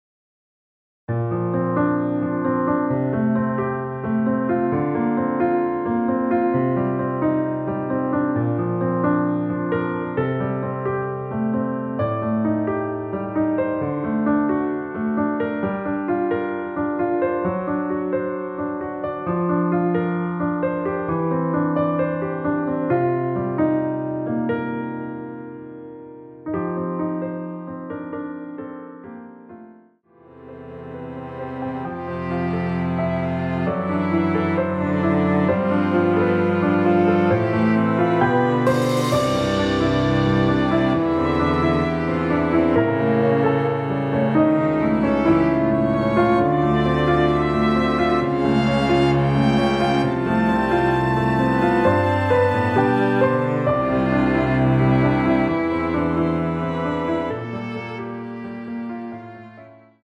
원키에서(-6)내린 남성분이 부르실수 있는 키의 MR입니다.
Bb
앞부분30초, 뒷부분30초씩 편집해서 올려 드리고 있습니다.